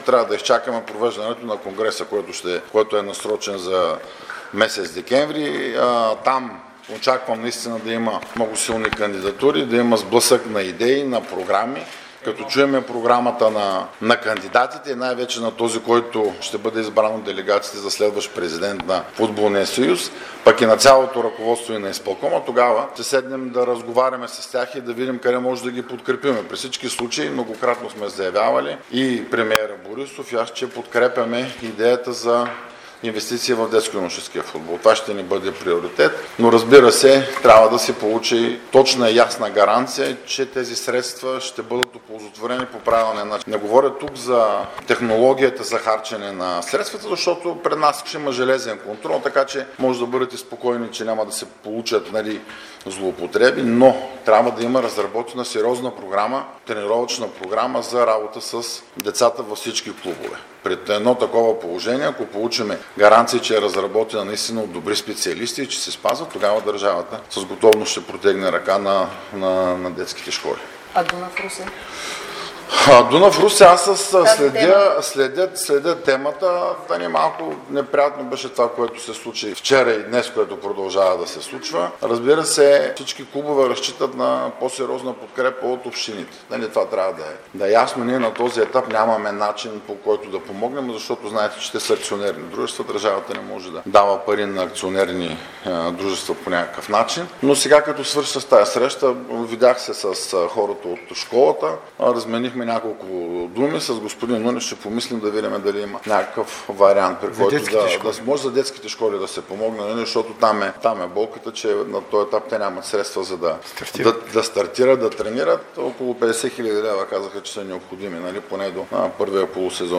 Министърът на младежта и спорта Красен Кралев сподели пред журналисти в Русе, че следи ситуацията, в която се намира местния Дунав. Той обаче уточни, че на този етап държавата няма как да помогне, защото става дума за акционерни дружества.